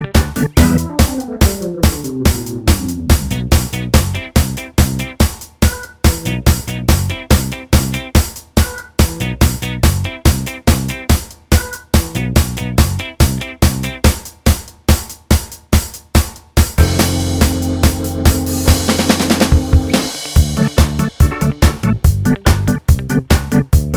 Minus Main Guitar Ska 3:16 Buy £1.50